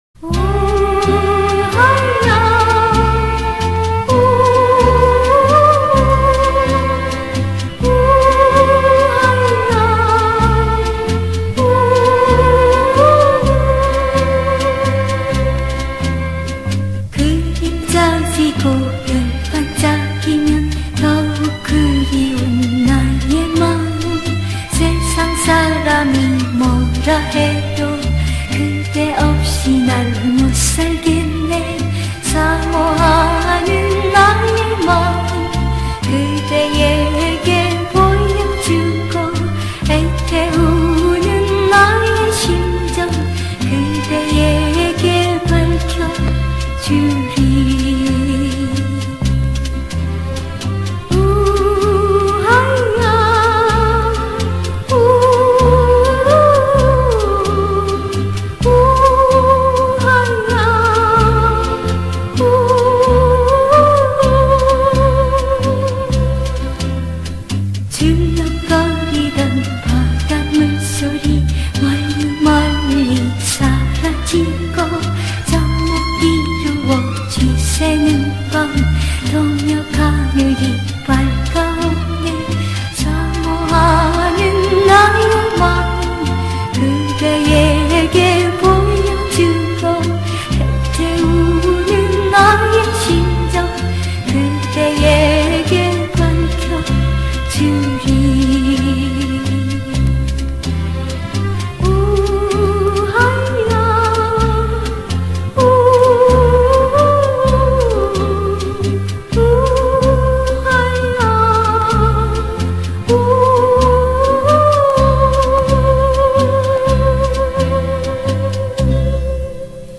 번안곡